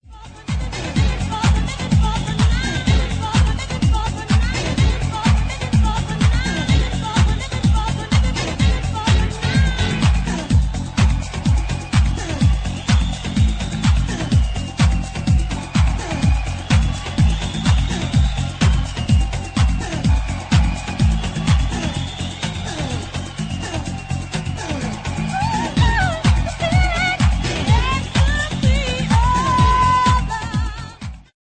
Norwegian funk band
pure afro funk, disco madness
Disco Funk